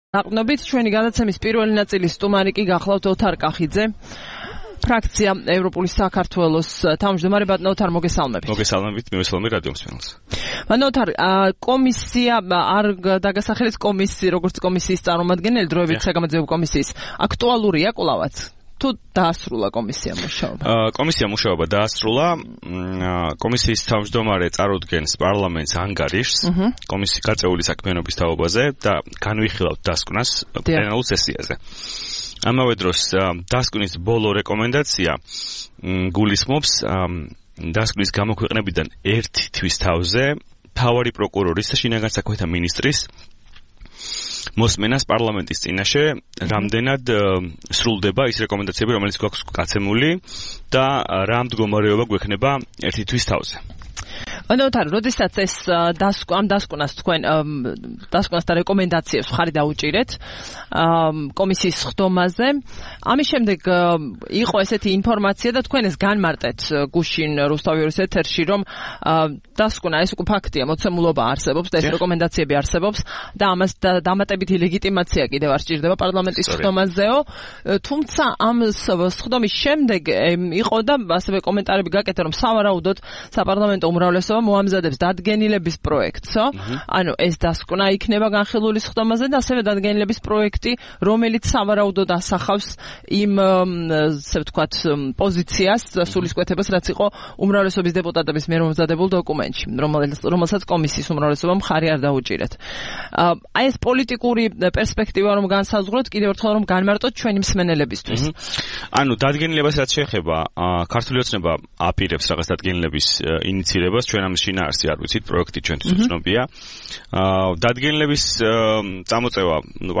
12 სექტემბერს რადიო თავისუფლების "დილის საუბრების" სტუმარი იყო ოთარ კახიძე, ფრაქცია "ევროპული საქართველოს" თავმჯდომარე.